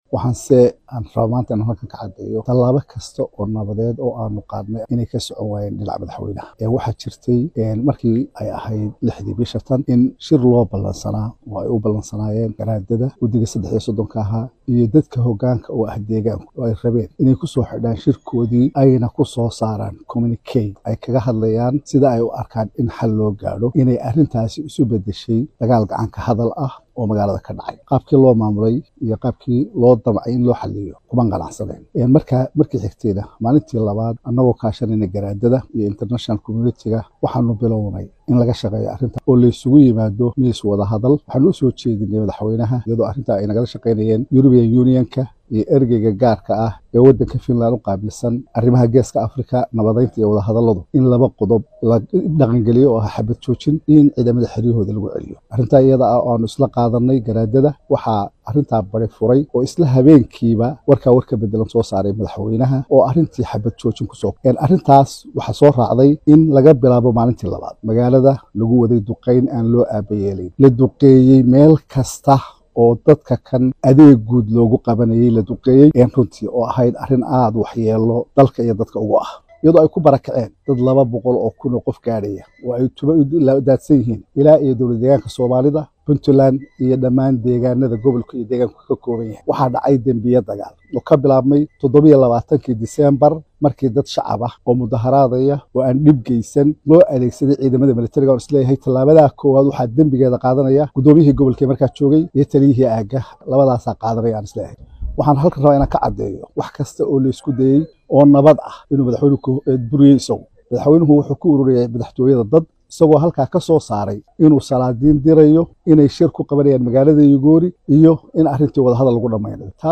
Guddoomiyaha Baarlamaanka Somaliland Cabdirisaaq Khaliif ayaa Saddex toddobaad ka dib waxaa uu si kulul uga hadlay dagaalka ka socda Magaalada Laascaanood ee Xarunta Gobolka Sool, kuwaas oo u dhexeya Ciidamada deegaanka iyo kuwa Somaliland.